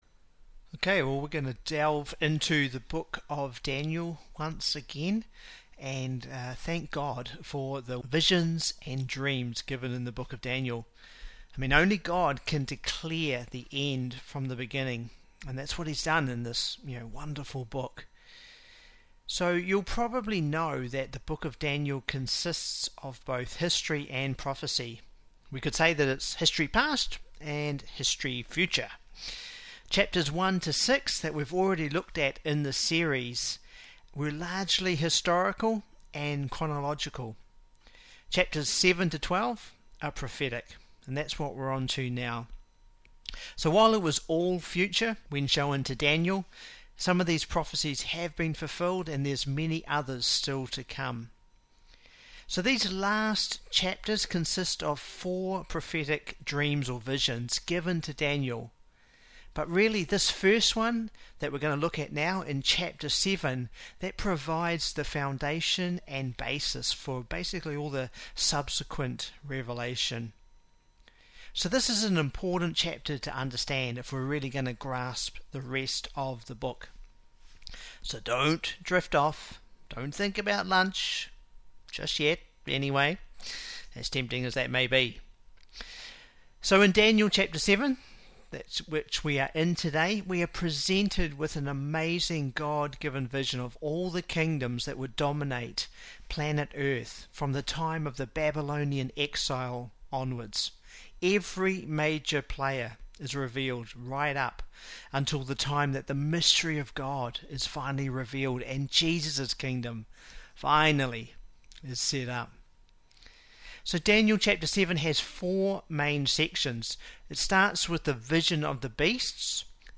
Message on Daniel 7 concerning Daniel's vision of four beasts that will dominate this world.